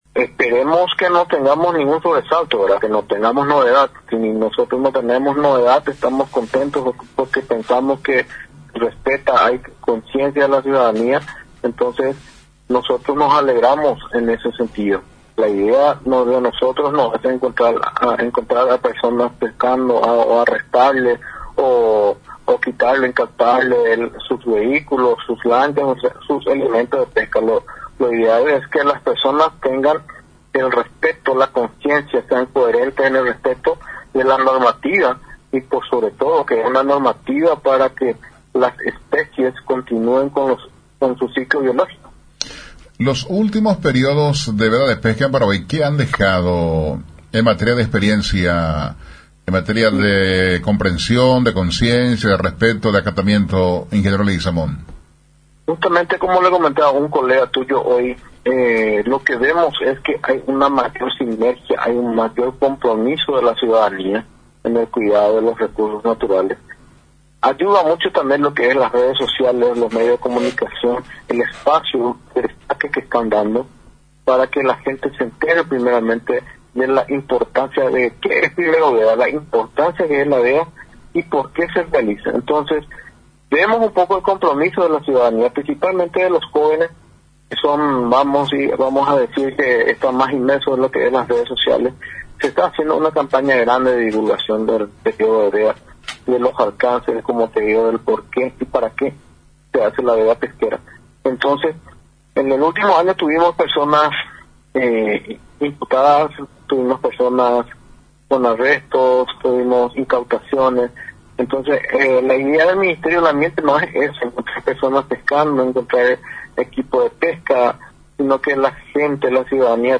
Nota: Ing. Adam Leguizamón-Director Nacional de Pesca y Acuicultura del MADES